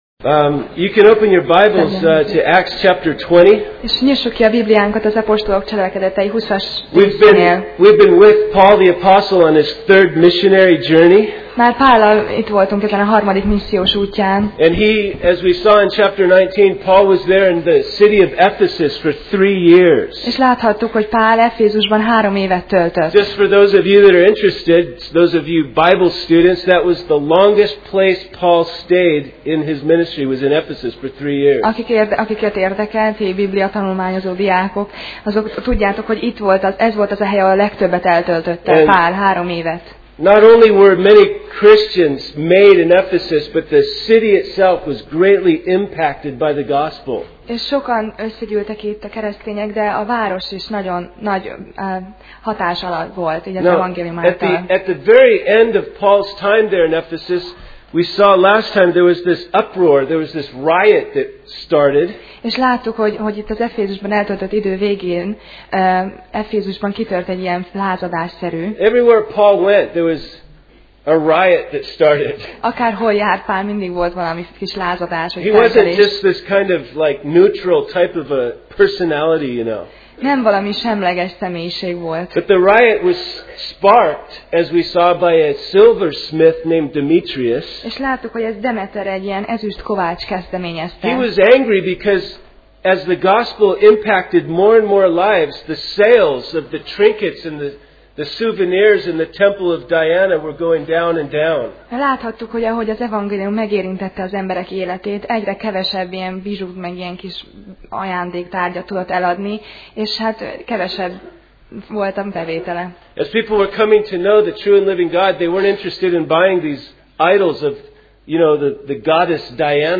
Sorozat: Apostolok cselekedetei Passage: Apcsel (Acts) 20:1-17 Alkalom: Vasárnap Reggel